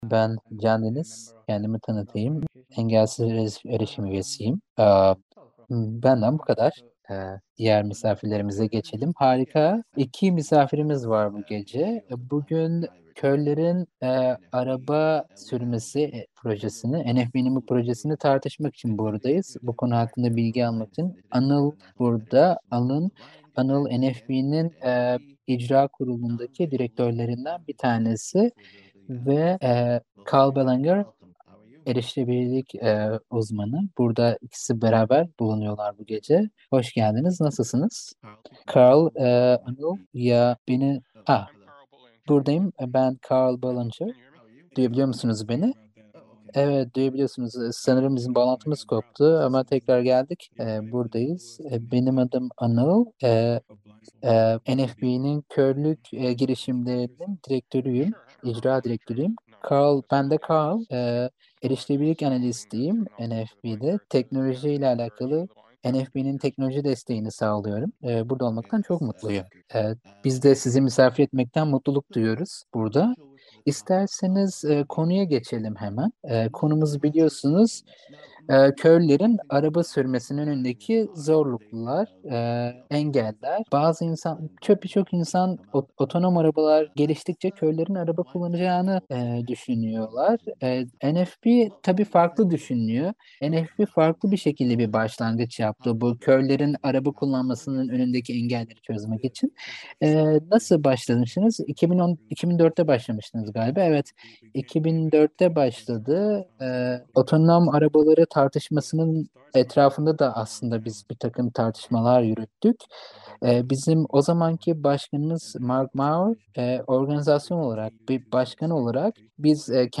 9. Beyaz Baston ve Erişilebilirlik Festivali (Kör Şoförle Festivalde Akşam Yolculuğu) 21.10.2022 | Dünyaya Seslen
Engelsiz Erişim ekibi olarak düşündük ki, çevrimiçi festivalin kazanımlarından birisini bu seneye taşıyalım ve akşam etkinliklerimizden birine yurt dışından bir misafiri çevrimiçi olarak davet edelim.